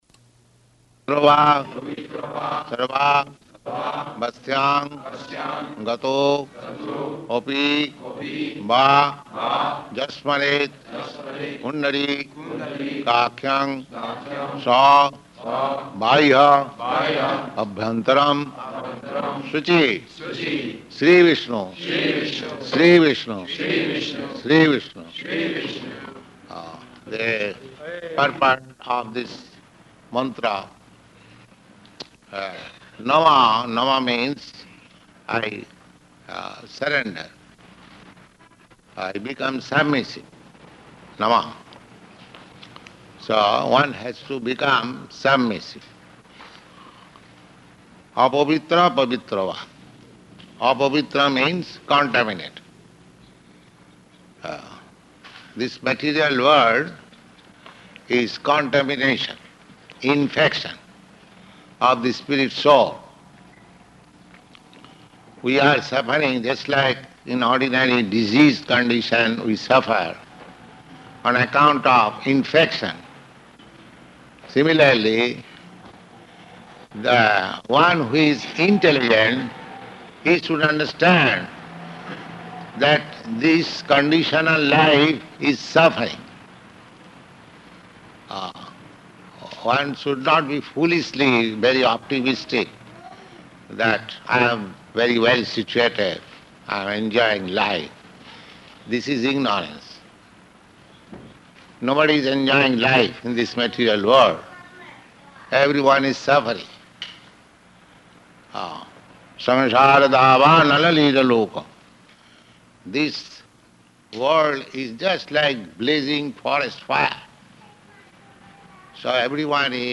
Lecture at Initiation Fire Sacrifice
Type: Initiation
Location: Los Angeles